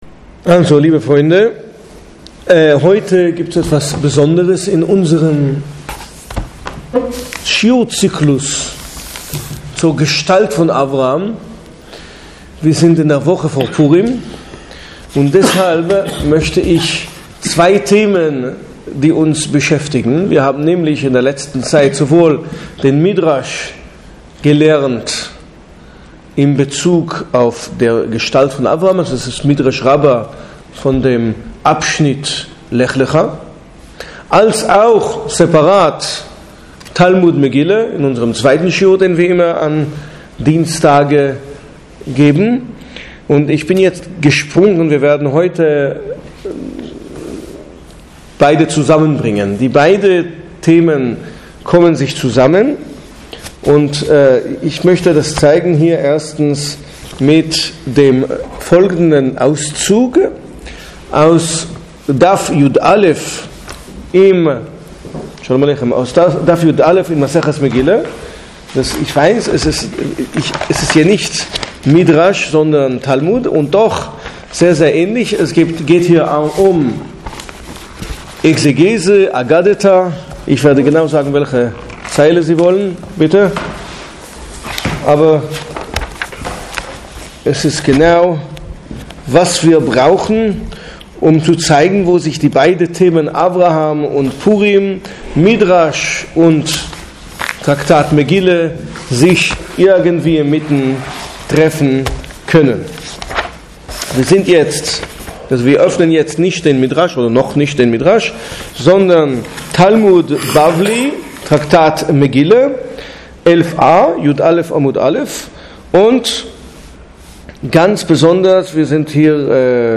Zur Vortrags-Serie: Obwohl er eine der bedeutendsten biblischen Persönlichkeiten ist, erzählt die Torá immerhin nur…